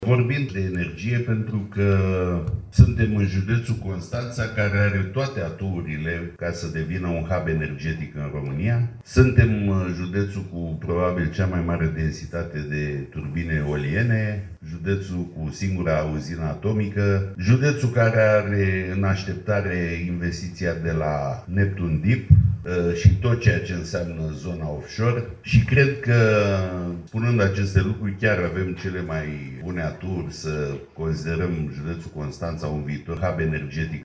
Conferința SMART ENERGY 2025 organizată azi la Constanța a adus în prim plan energia și, mai precis, viitorul energiei inteligente între inovație, digitalizare și eficiență.